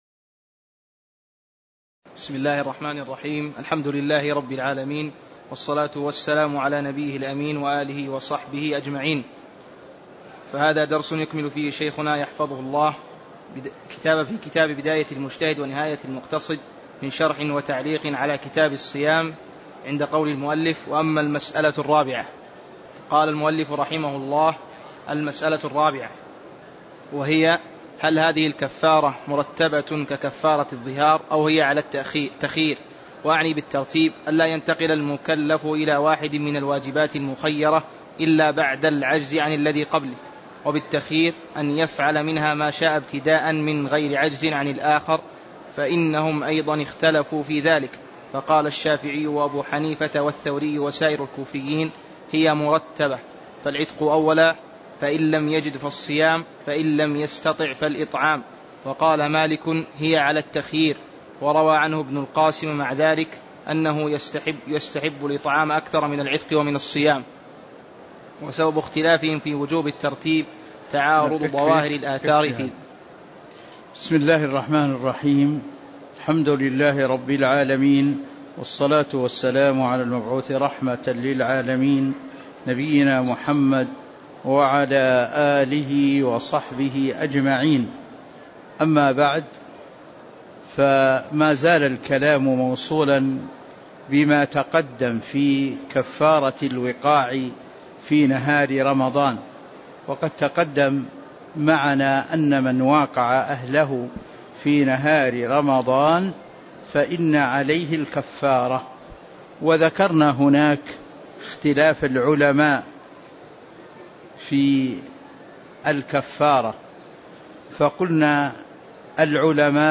تاريخ النشر ٥ رجب ١٤٤٦ هـ المكان: المسجد النبوي الشيخ